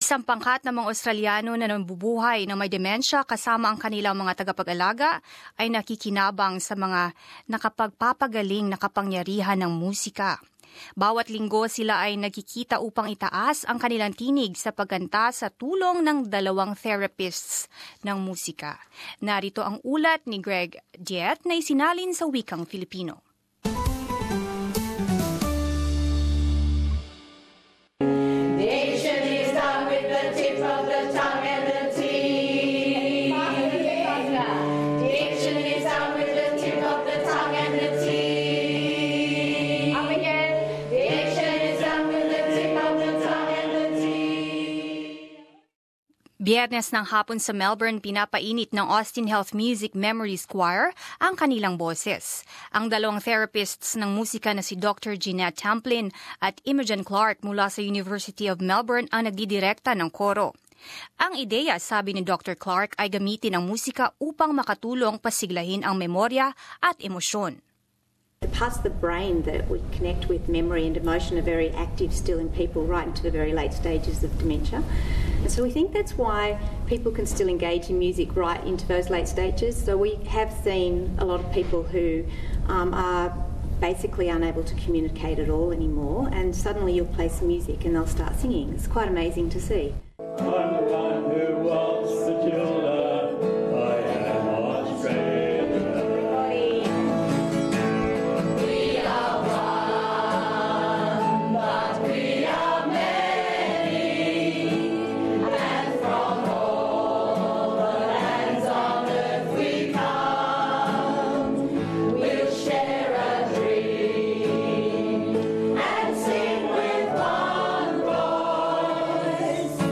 A group of Australians living with dementia, along with their carers, is benefiting from the healing powers of music. Each week, they all meet to raise their voices in song with the help of two music therapists.